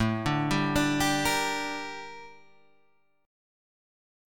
Gm/A chord